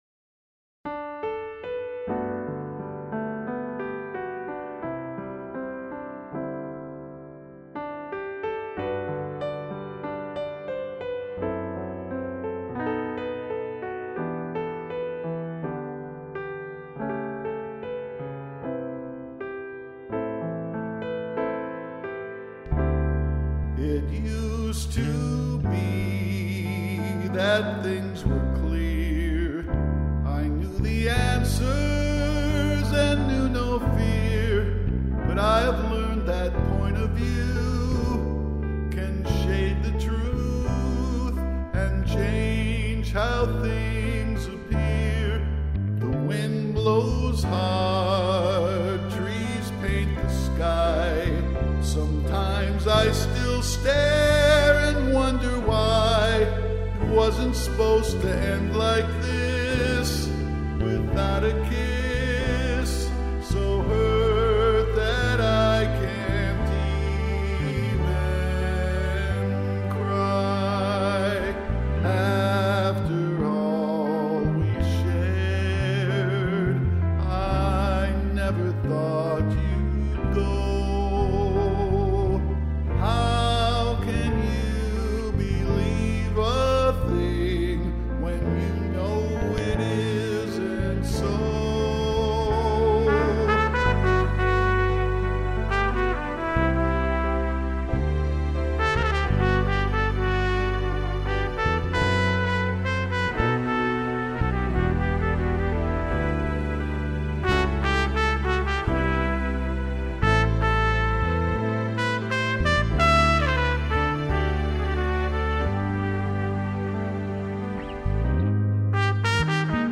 Trumpet
Bass